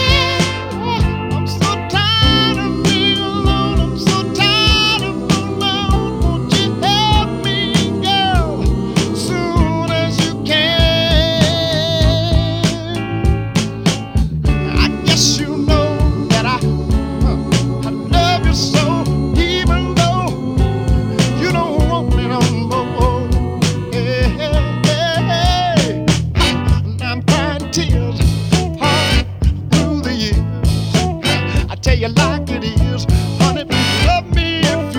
Жанр: Соул